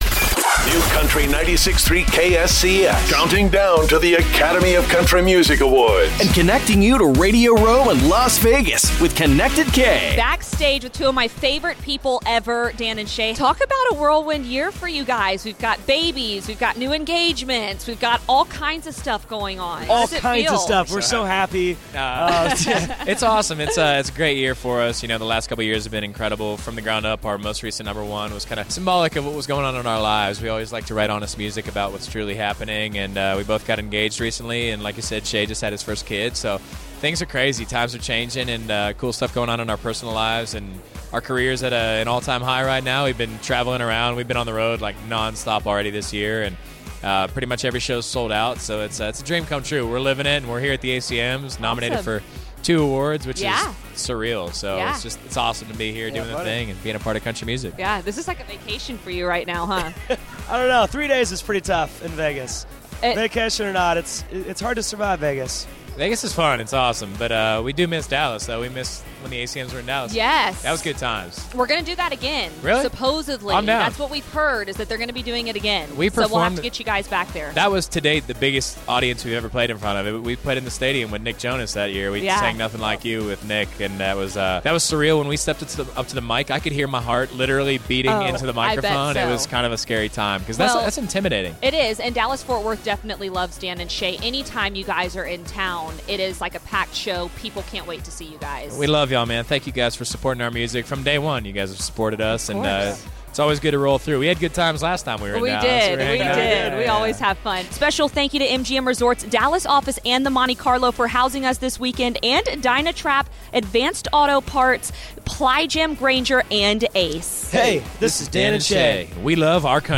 ACM Interview Dan + Shay